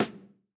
Snare Zion 5.wav